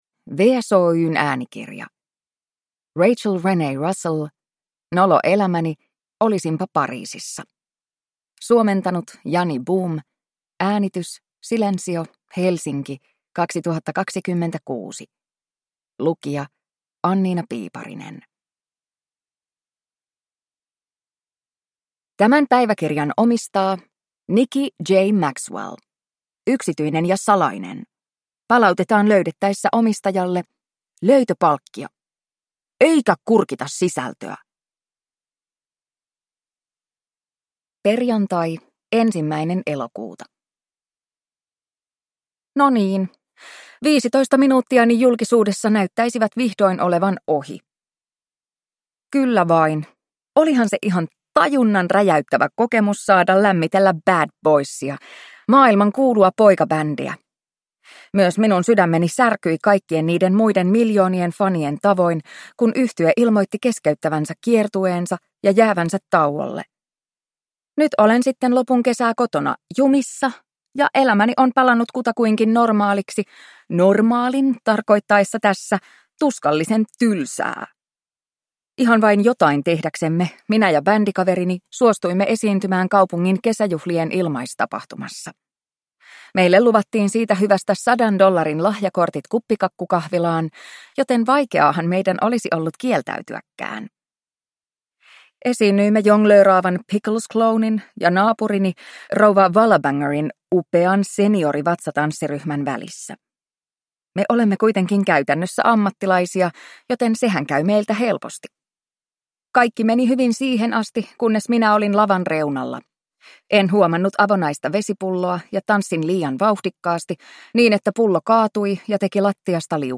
Nolo elämäni 15: Olisinpa Pariisissa – Ljudbok